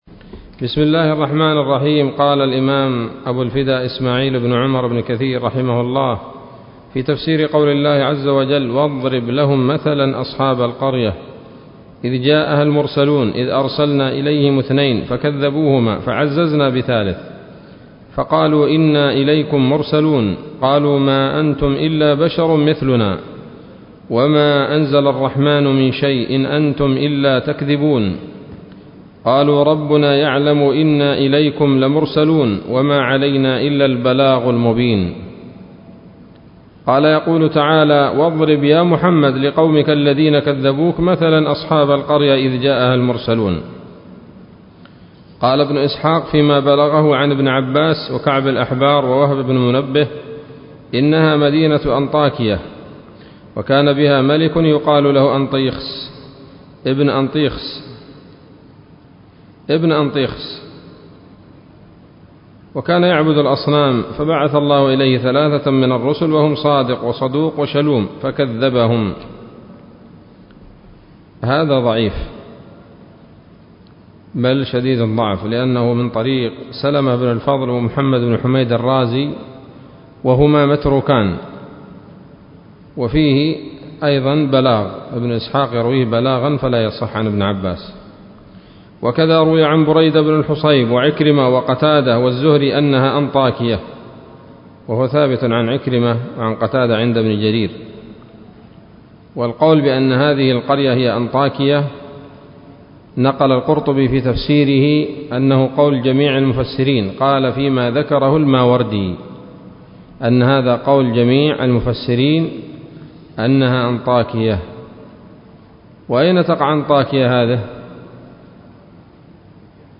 الدرس الرابع من سورة يس من تفسير ابن كثير رحمه الله تعالى